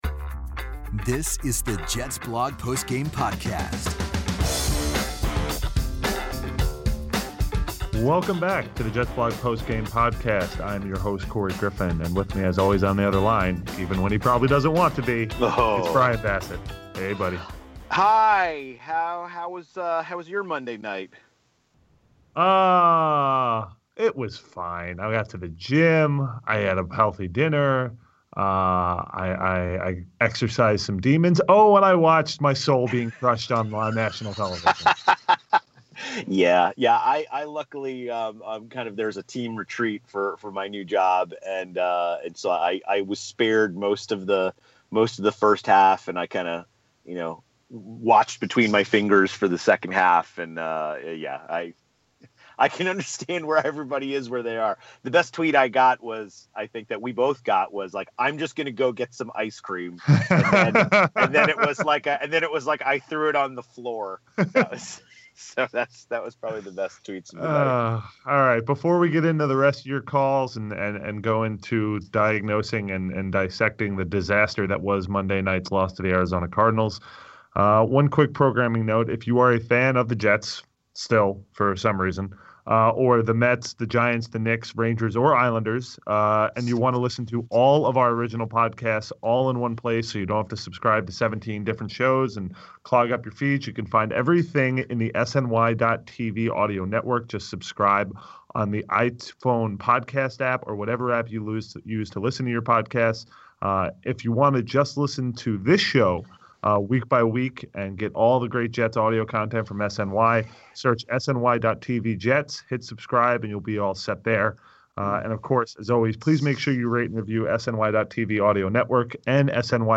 The guys answer calls from listeners venting about the quarterback, the coaching, and the disappointing defense.